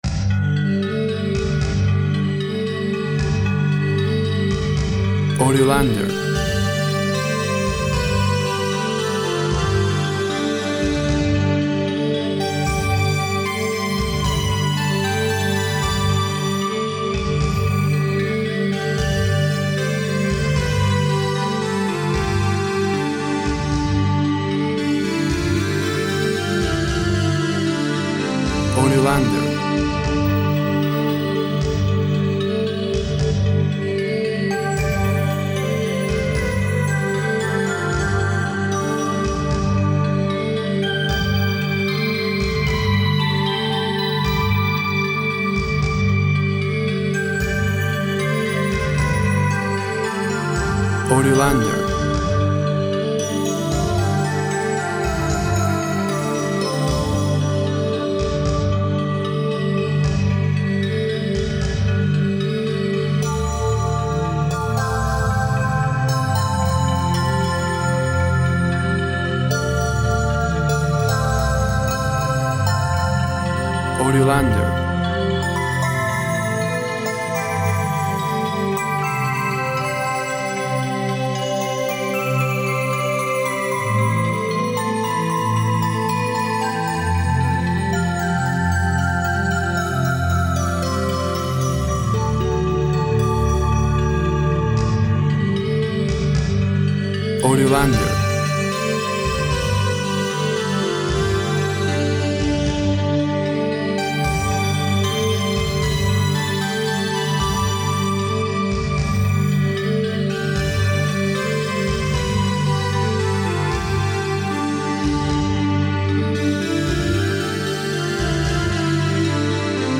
Energy and drive with synth and percussive sounds.
Tempo (BPM) 80